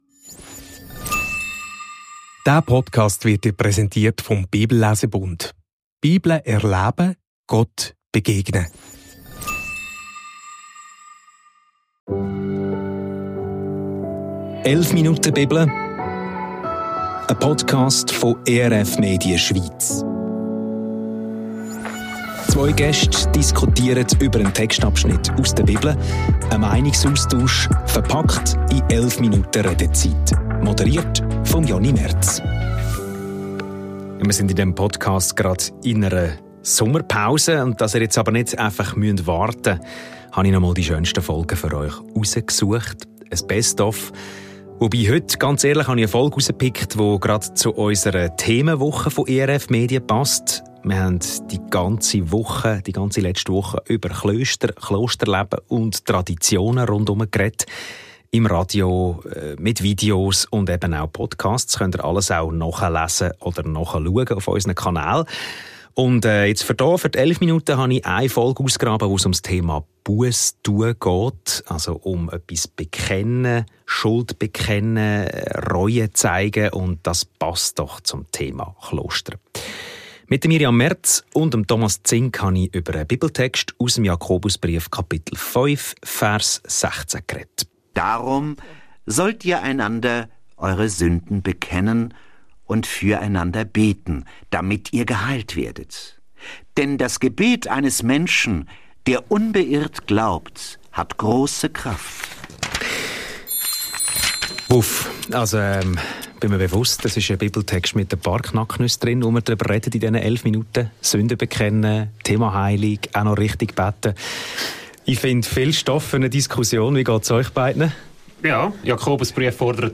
Die drei tauschen über ihre Empfindungen aus, ordnen den Text von Jakobus ein und landen am Schluss bei einer ganz persönlichen Erfahrung.